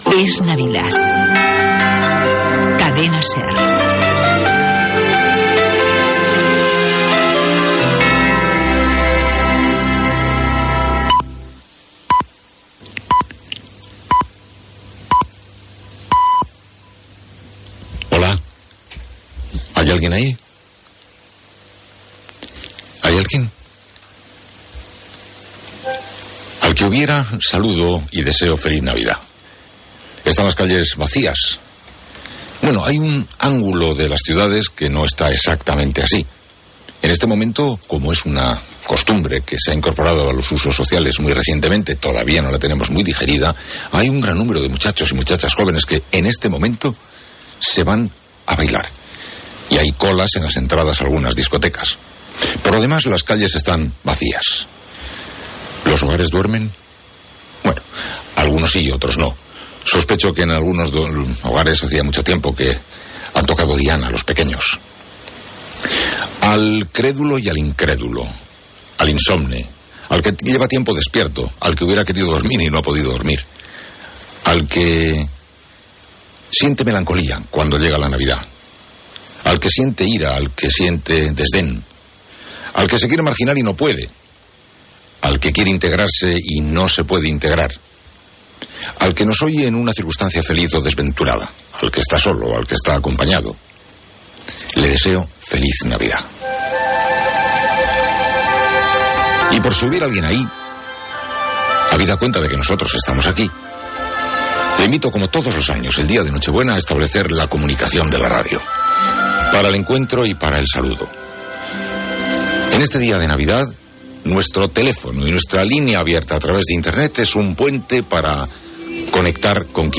Indicatiu nadalenc de la Cadena, senyals horaris, presentació del programa el matí del dia de Nadal, invitació a participar en el programa
Info-entreteniment